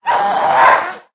scream5.ogg